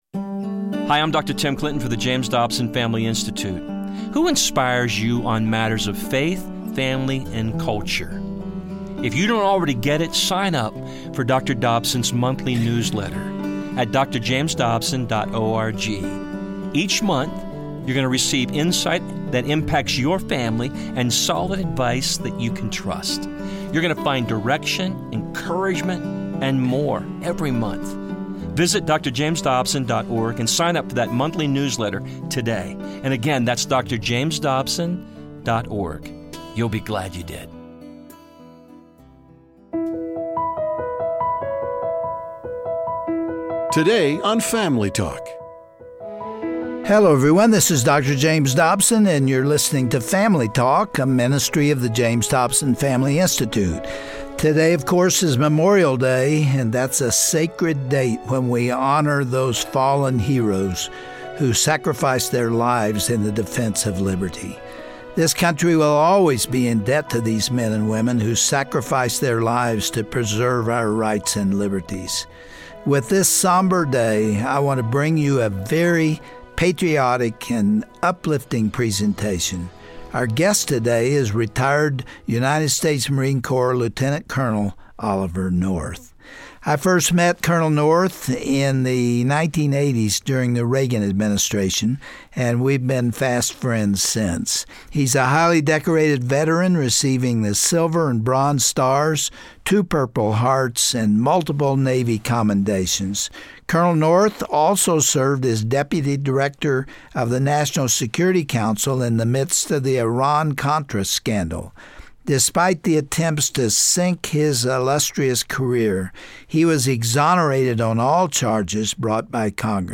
On this somber Family Talk broadcast, hear a moving presentation from decorated veteran, Lt. Colonel Oliver North. He honors those who have served this country, and highlights the importance of prayer in the darkest of hours.